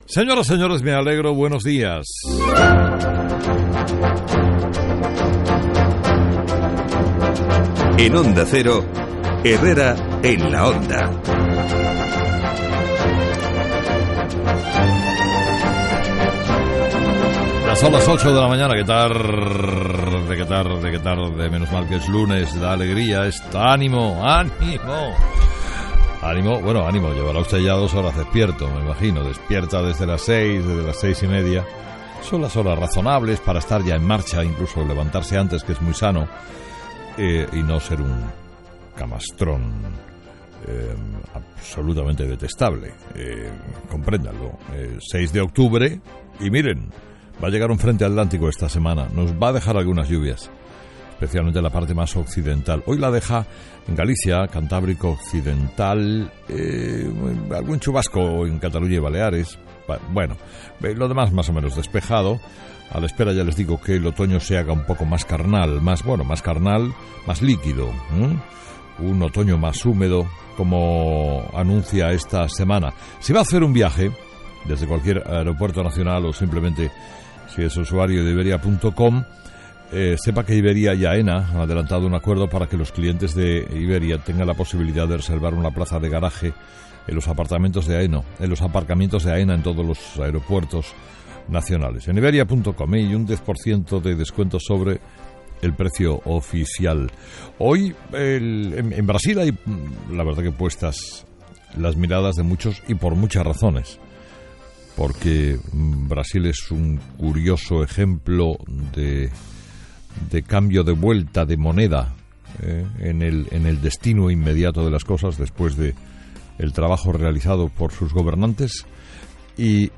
06/10/2014 Editorial de Carlos Herrera: ¿Cuándo parará el show de Artur Mas?